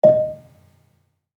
Gambang-D#4-f.wav